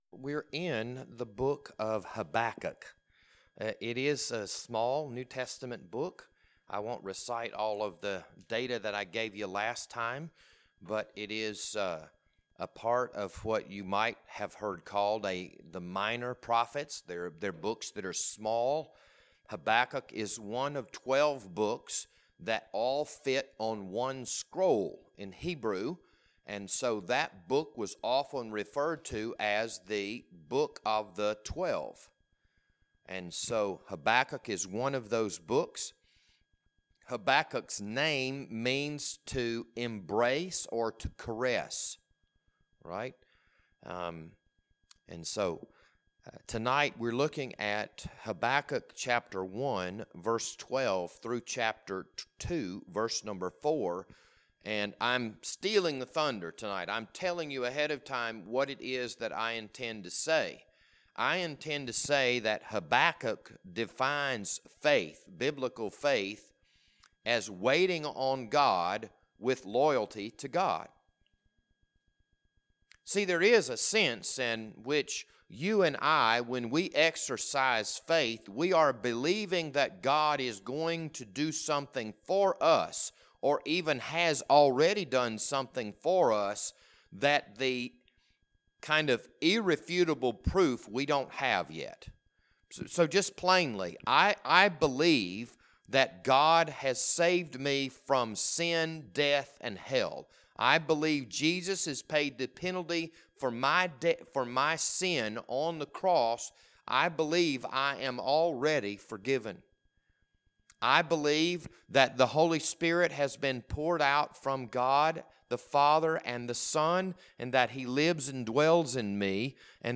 This Wednesday evening Bible study was recorded on April 14th, 2021.